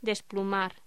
Locución: Desplumar
voz